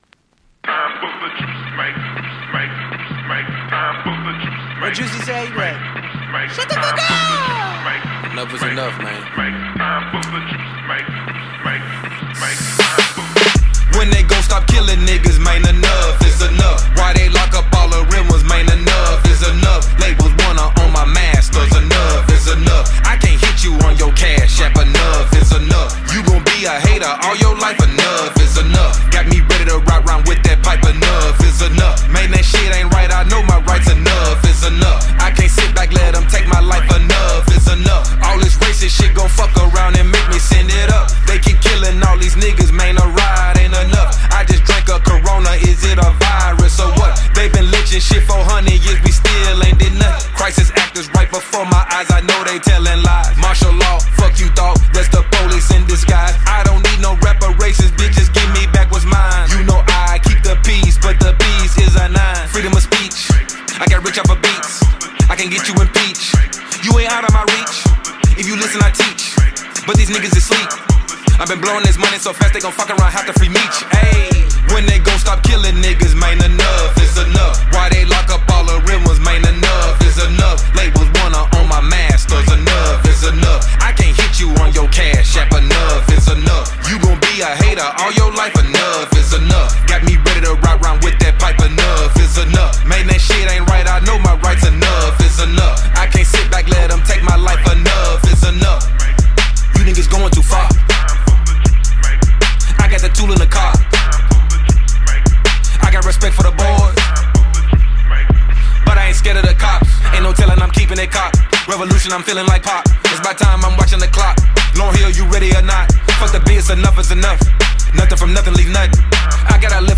The song is a self produced one.